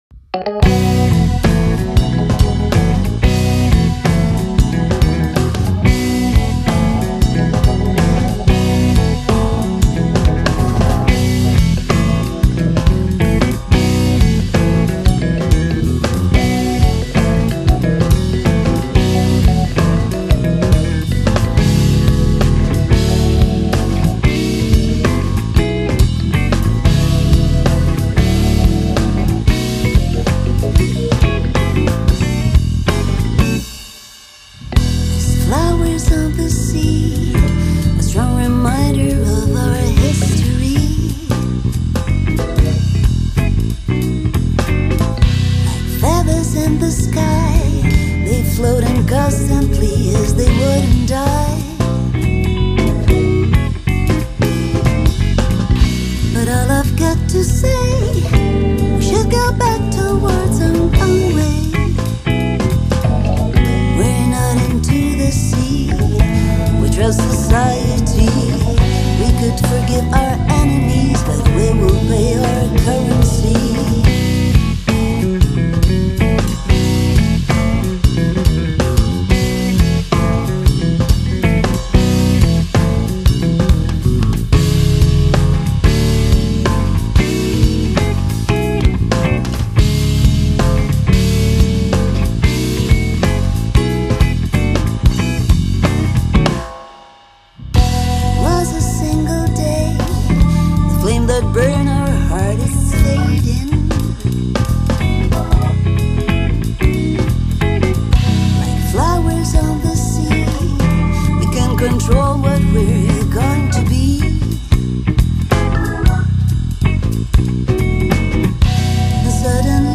chitarra
voce
batteria
basso
organo hammond